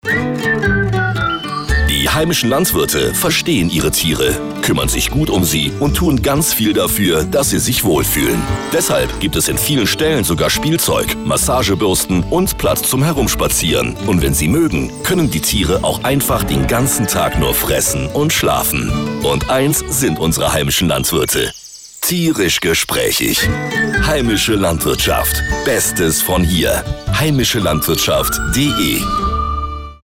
Radiospots zum Herunterladen